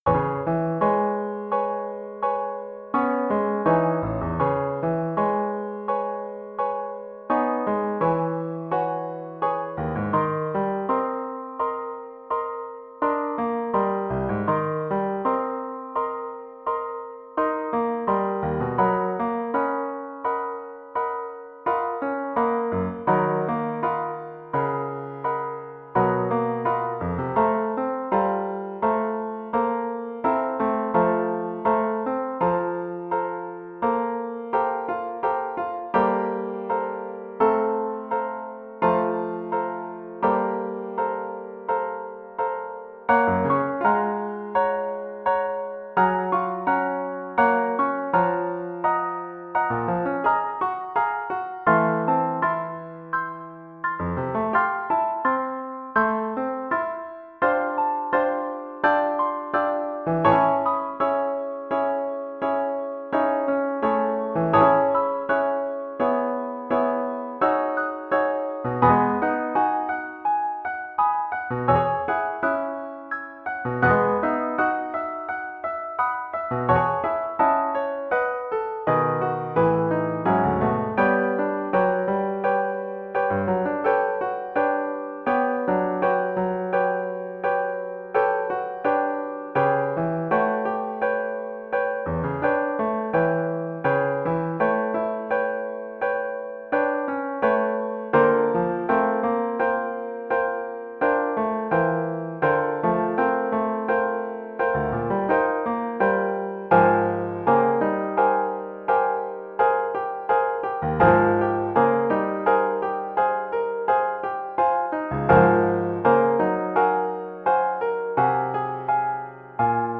The MP3s below are exported from the MIDI.
(instrumental)